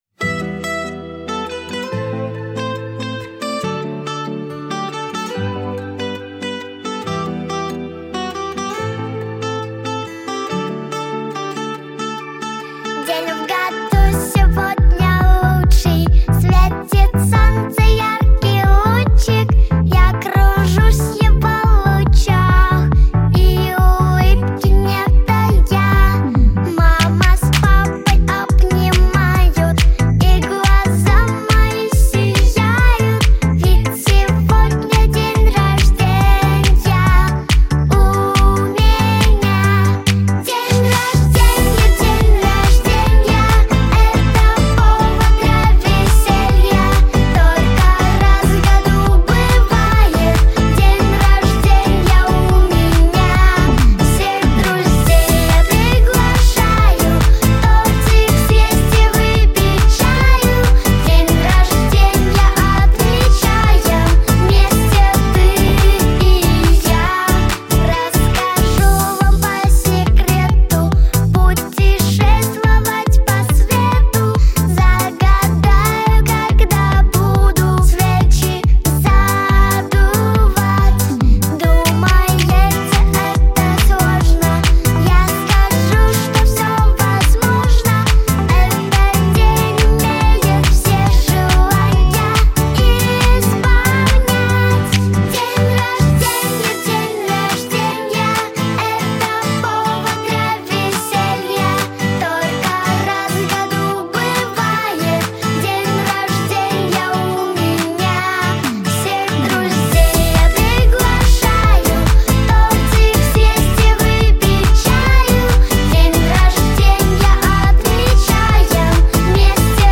• Категория: Детские песни / Песни про маму